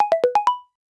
popcorn-quiet.aifc